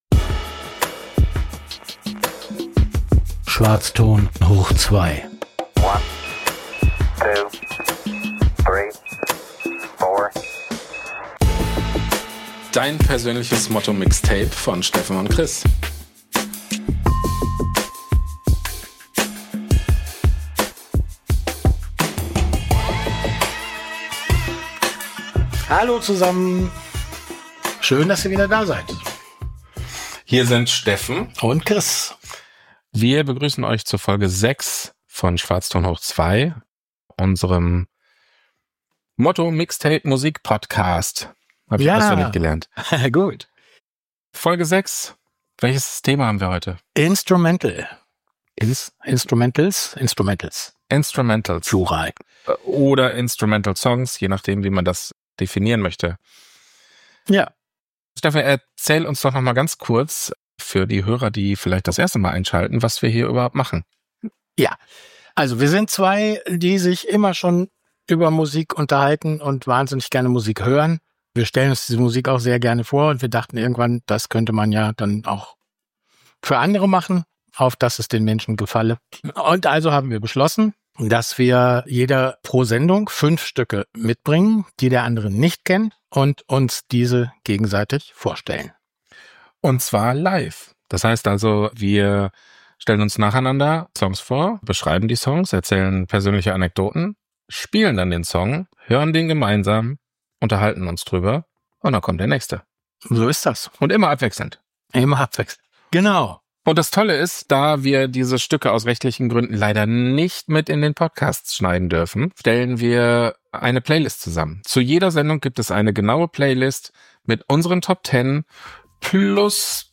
Aktuelle Musik-Playlist v06 Top20 Best Instrumental Songs: Spotify // Deezer // Amazon Music // Youtube (Video) // Youtube Music // Weil man Musik am besten selbst erlebt, und wir die Songs aus rechtlichen Gründen leider nicht in die Sendung einbauen können, erstellen wir auf allen gängigen Musik-Plattformen die passende Playlist.